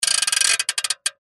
Скрип механизма древней куклы